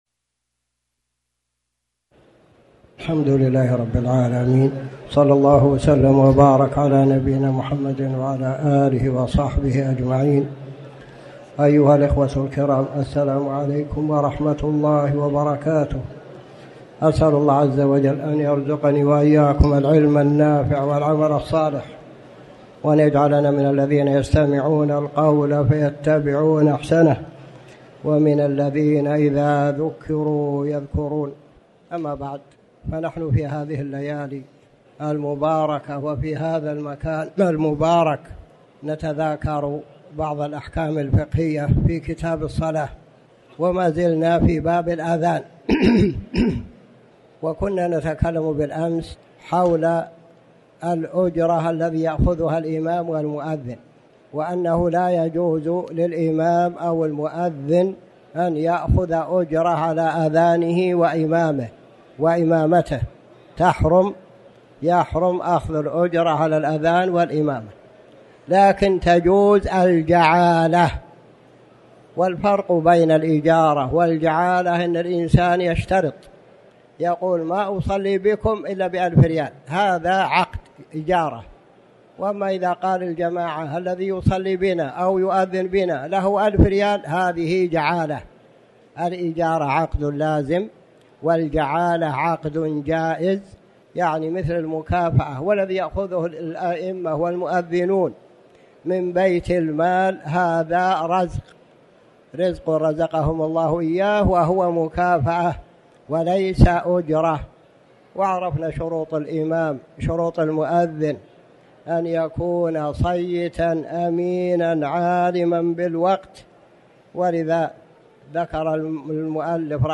تاريخ النشر ١٣ صفر ١٤٤٠ هـ المكان: المسجد الحرام الشيخ